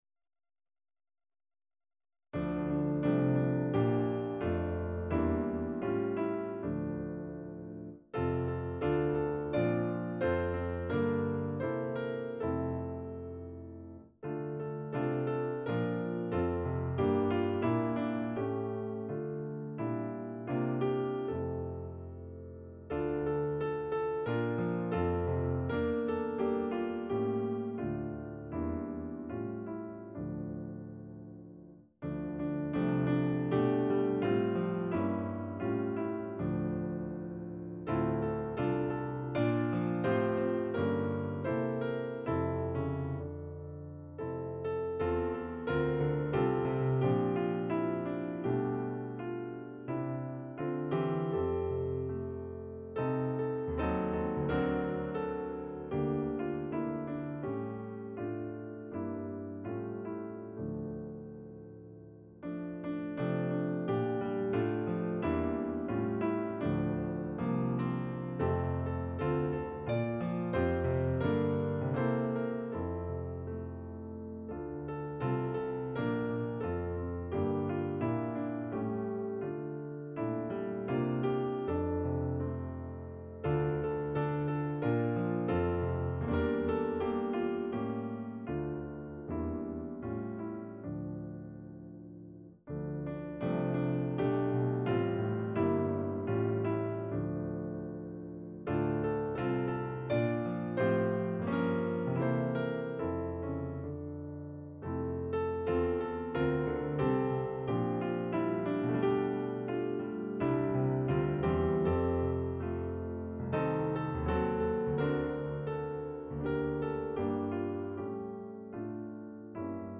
Na motywach melodii ludowej
akompaniament